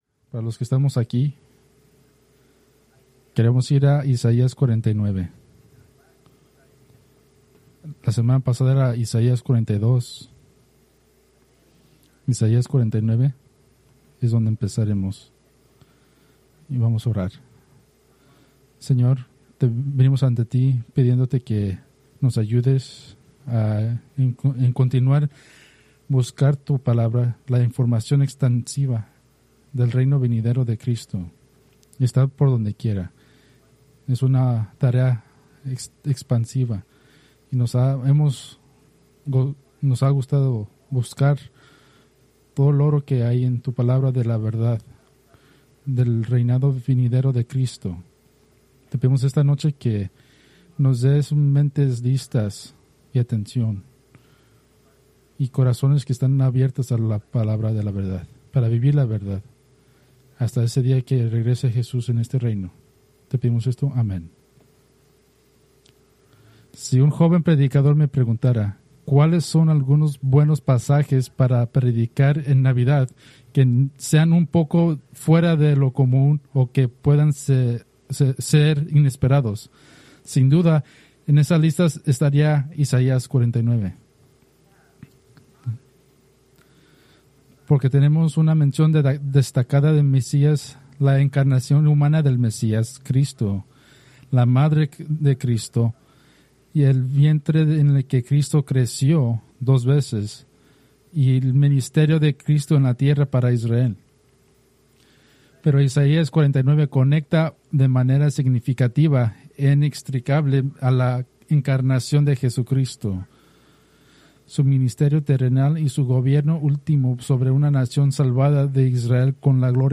Preached November 16, 2025 from Escrituras seleccionadas